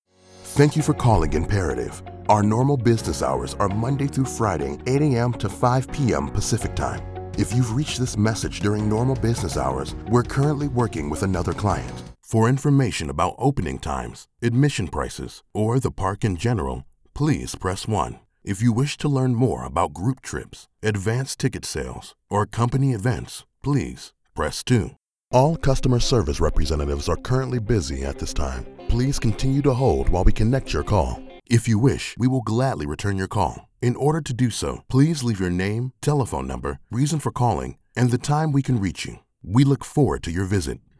voice has been described as Masculine, Ominous, Warm, Rich, Deep, Sexy and Smooth.
Mature Adult, Adult
IVR_Demo_2019.mp3